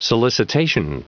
Prononciation du mot solicitation en anglais (fichier audio)
Prononciation du mot : solicitation